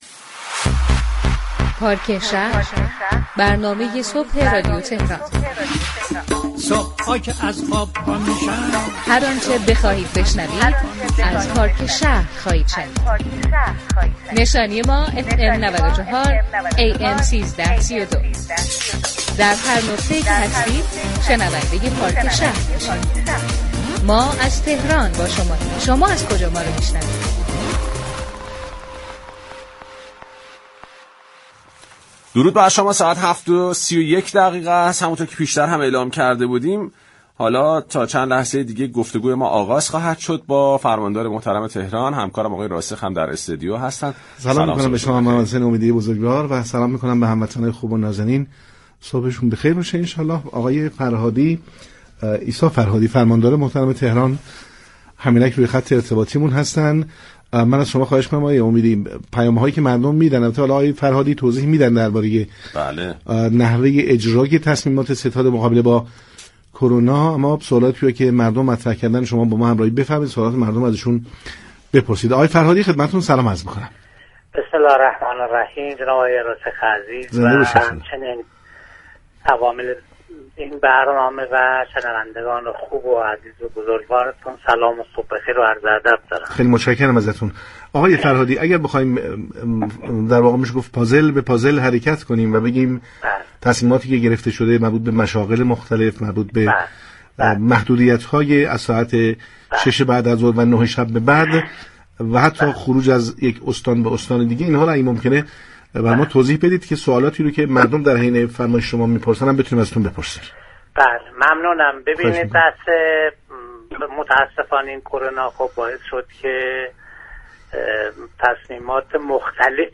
عیسی فرهادی فرماندار تهران در گفتگوی تلفنی با برنامه پارك شهر رادیو تهران جزئیات طرح محدودیت های كرونایی 1 تا 14 آذر را تشریح كرد.